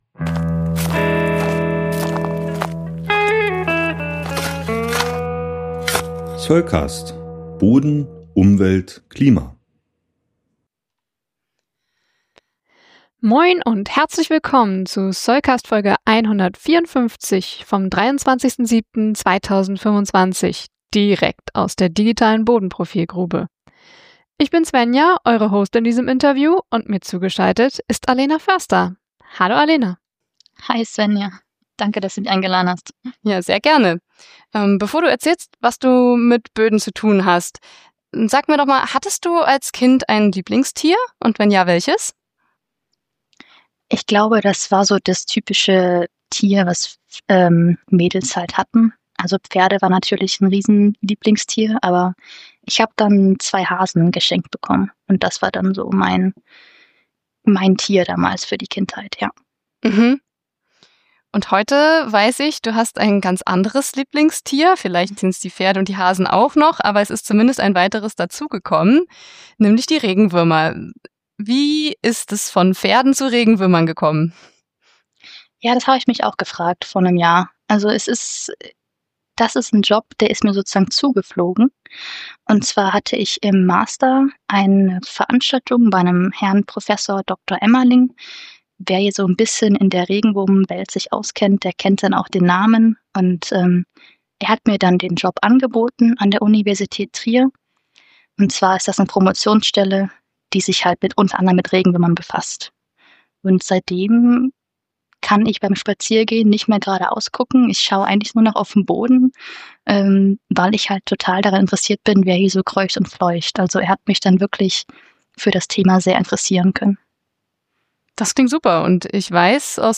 SC154 Interview: Würmische Zeiten ~ Soilcast Podcast